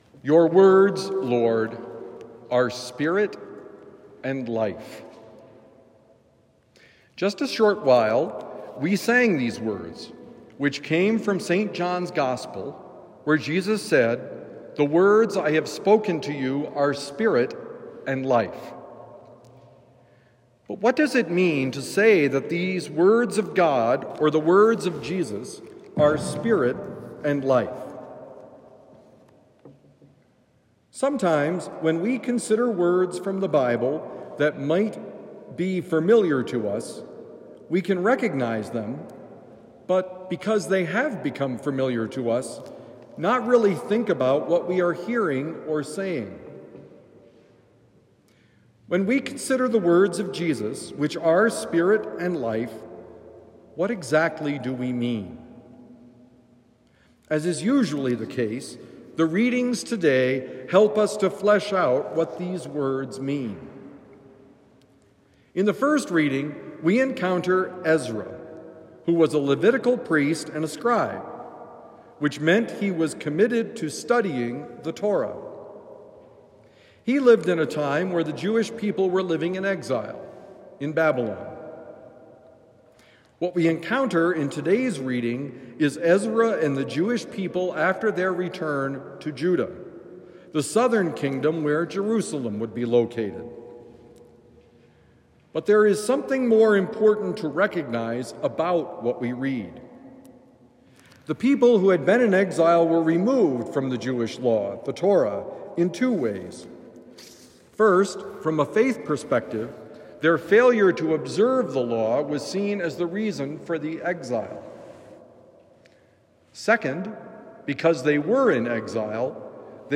Celebrate the Jubilee: Homily for Sunday, Janaury 26, 2025